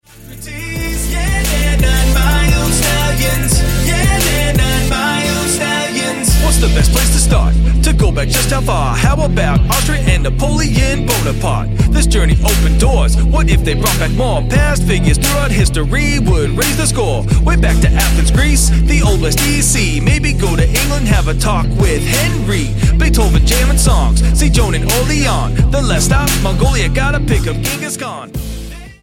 STYLE: Hip-Hop
intentionally simple old skool vocal flows